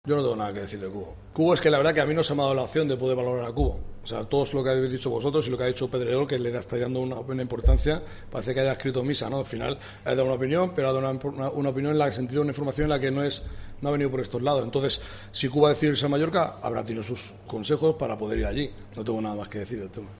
El técnico catalán negó en la rueda de prensa previa al duelo ante el Real Madrid que el japonés hubiese estado cerca de firmar por el Valladolid.